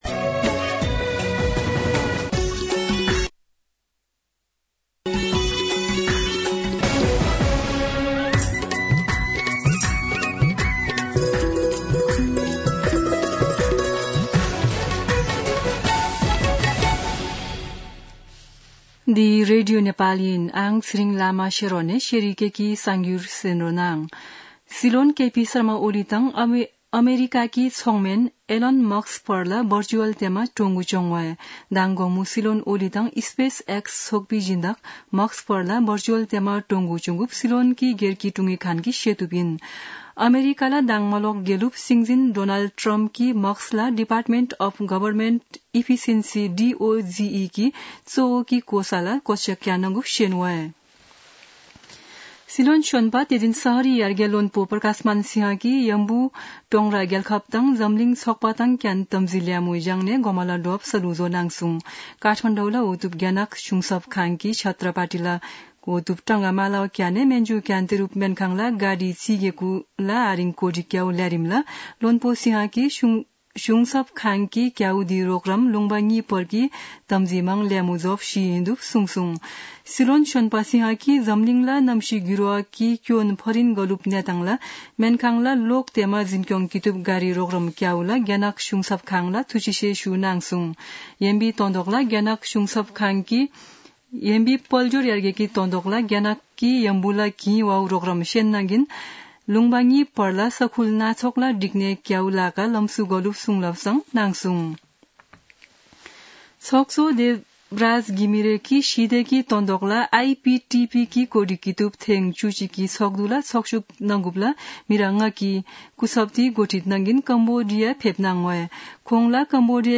शेर्पा भाषाको समाचार : ९ मंसिर , २०८१
4-pm-Sherpa-news.mp3